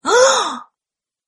Woman Shocking Gasp Sound Effect Free Download
Woman Shocking Gasp